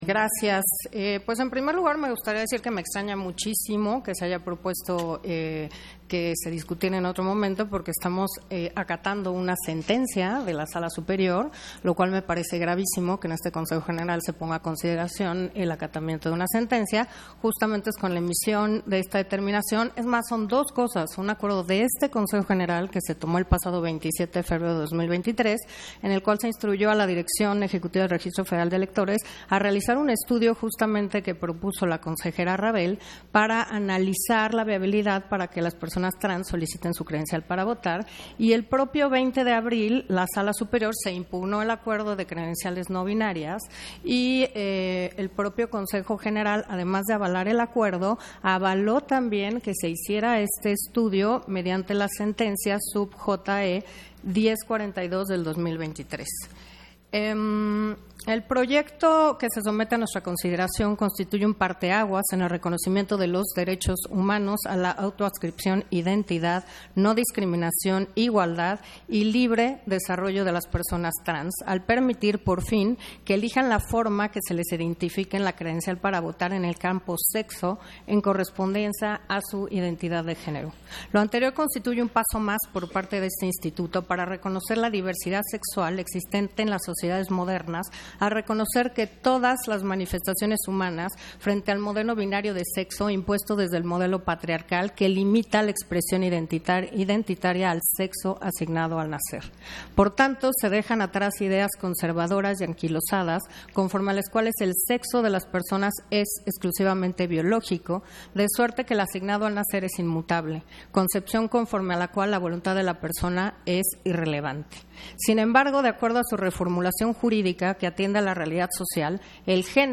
Intervención de Carla Humphrey, en el punto 9 de la Sesión Extraordinaria, por el que se determina viable que personas trans que soliciten su credencial para votar, se les expida la credencial con el identificador M o H acorde a su identidad de género